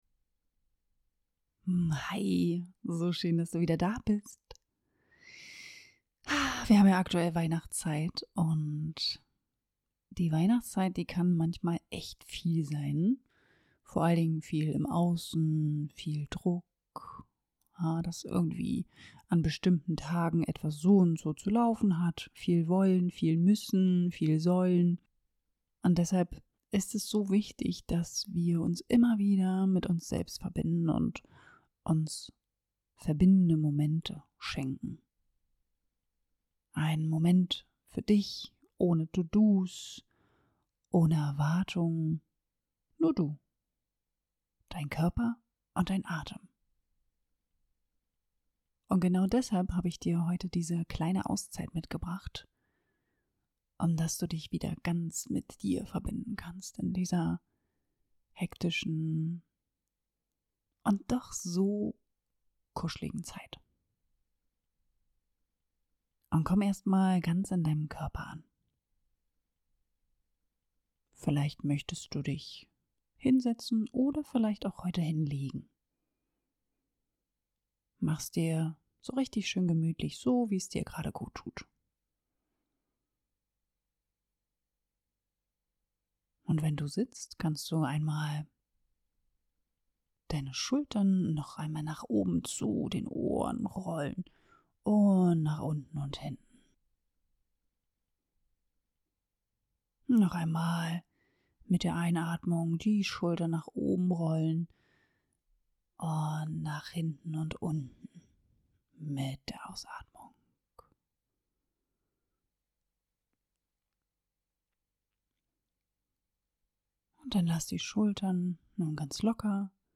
#60 -Weich werden im Weihnachtswirbel - eine achtsame Pause für dich (ohne Musik) ~ Körperweg Podcast
Diese kurze Meditation ist wie ein innerer Kamin: Eine Einladung, weich zu werden.
Ich habe dir eine Version mit Musik kreiert und eine ohne Musik, denn manchmal kann reine Stille nur mit Stimme auch unglaublich wohltuend sein.